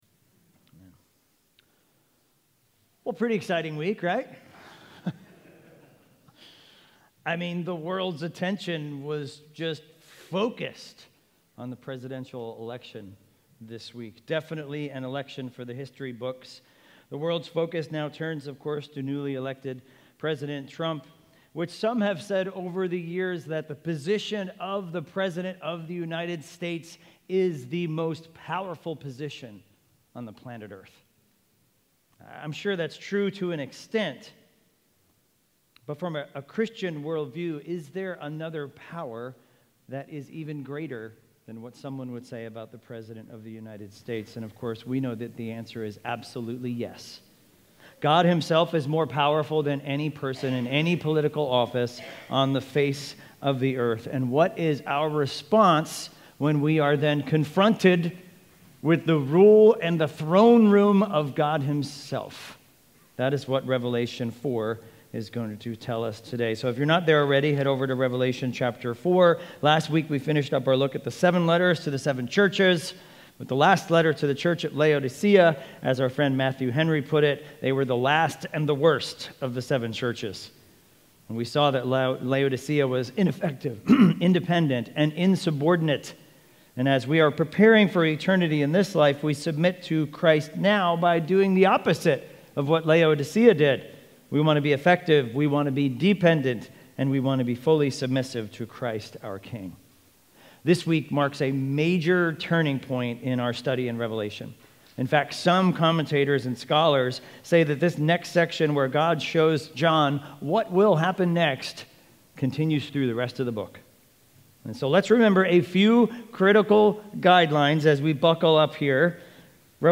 Expositional preaching series through the book of Revelation.